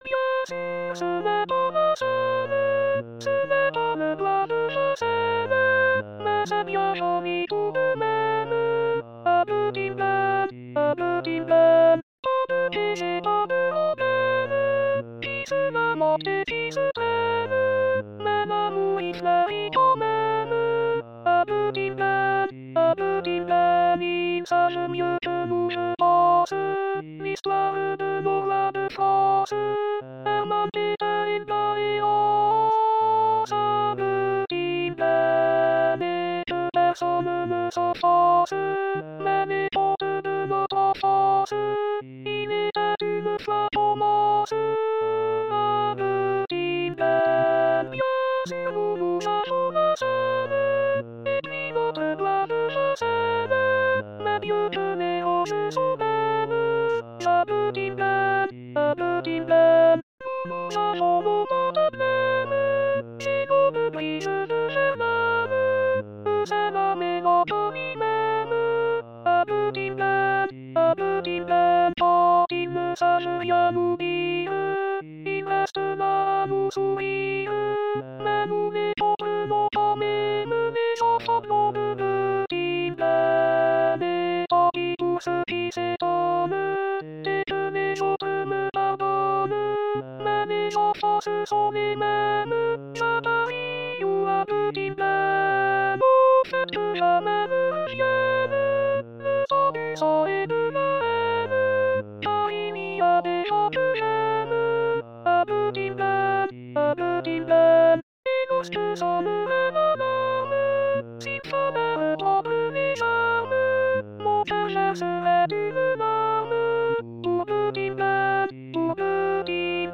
Soprano/Alto (.mp3)